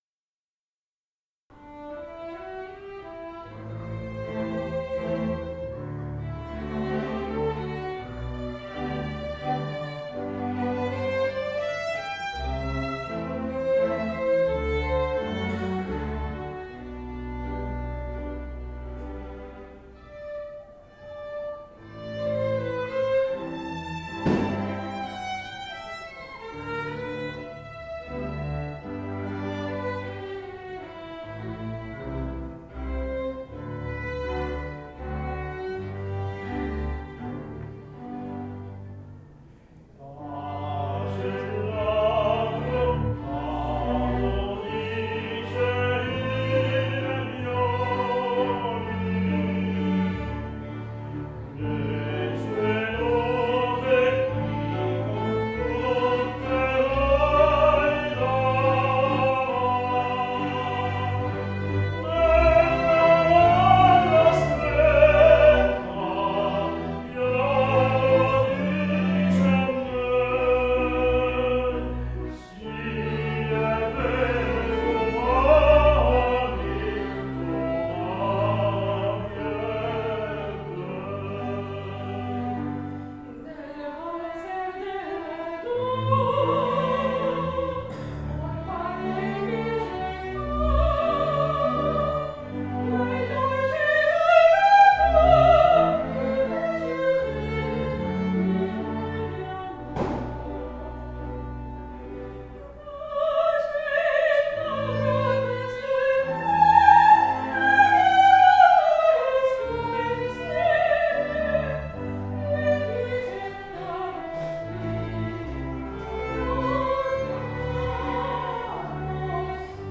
tenore
soprano
Dal Concerto del 16 ottobre 2016